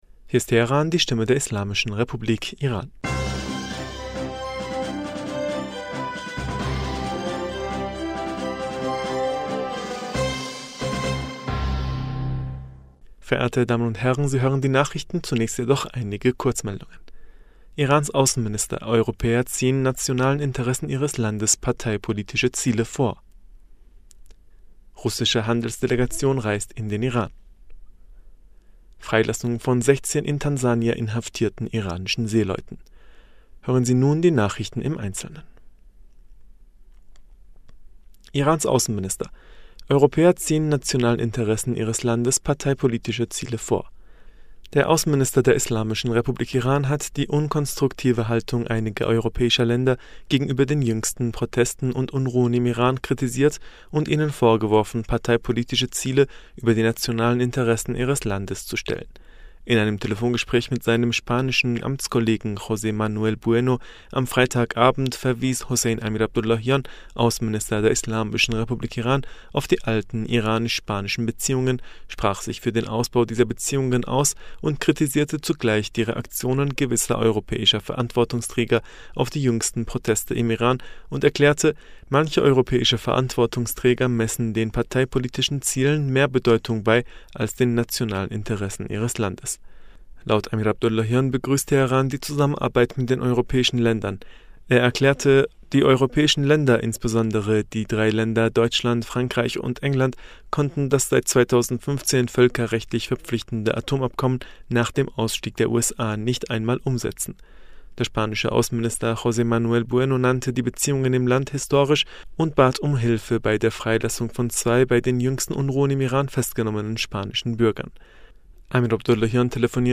Nachrichten vom 12. November 2022